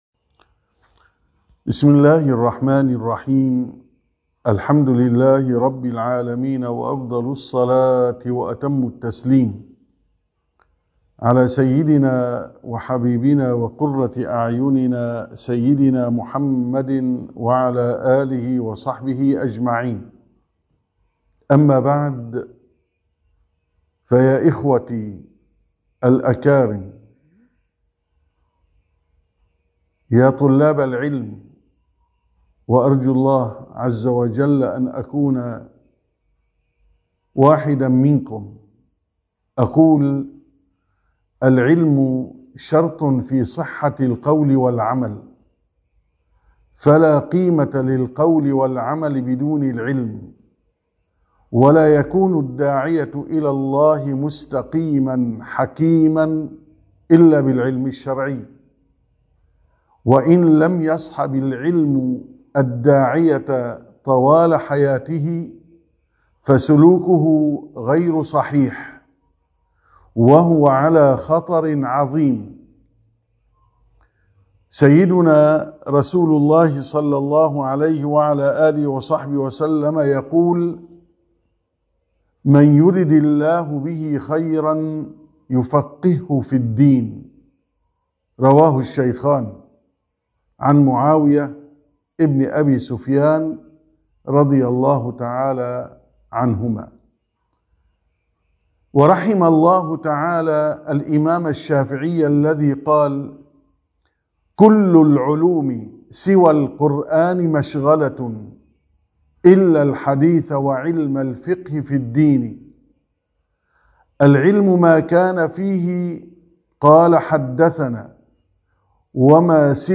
14-كلمة مختصرة في صلاة التراويح 1445هــ: حرّر نيتك في إعطاء زكاتك